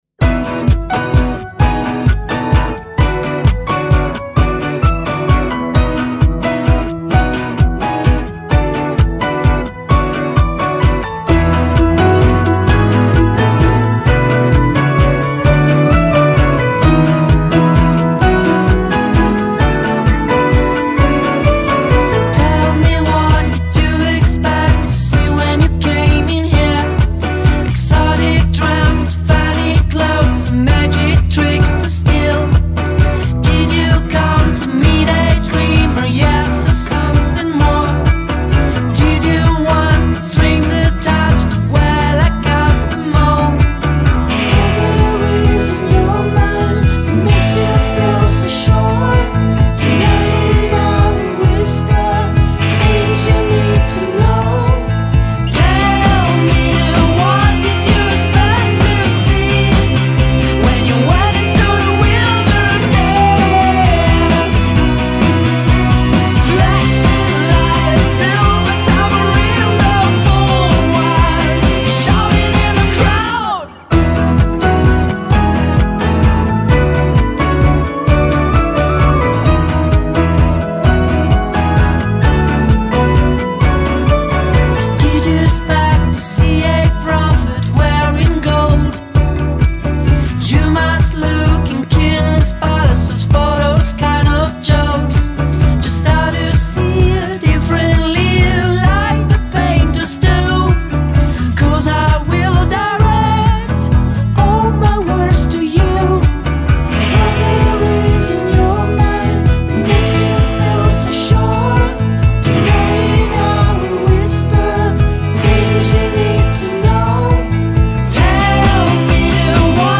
with their brand of indie psychedelic pop.
sunshine psych songs